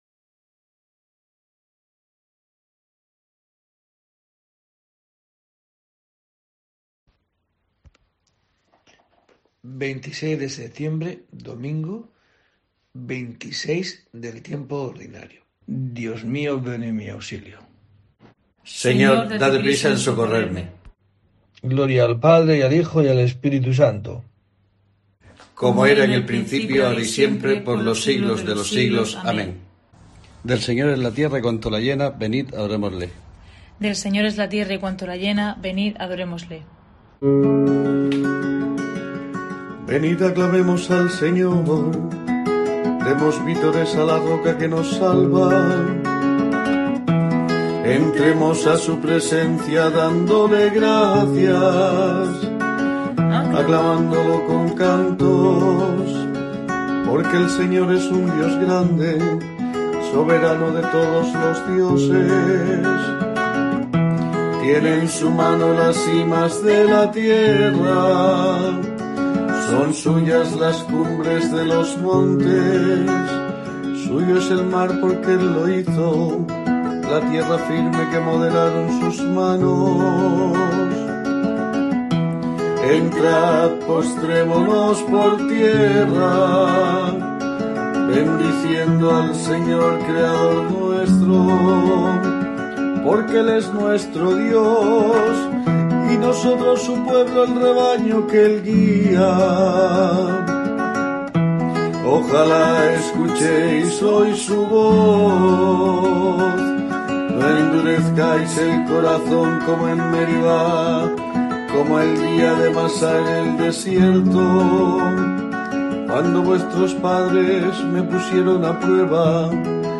25 de septiembre: COPE te trae el rezo diario de los Laudes para acompañarte